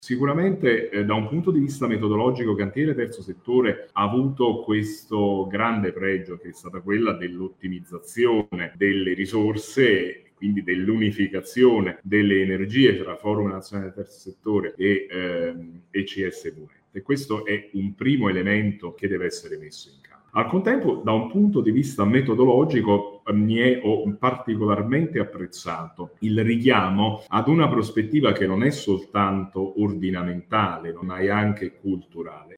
La riforma del Terzo settore e la sfida di una comunicazione efficace è stato il tema dell’incontro promosso da Csvnet e Forum nazionale. Ascoltiamo Alessandro Lombardi, Direttore Generale del Terzo Settore del ministero del Lavoro e delle Politiche sociali.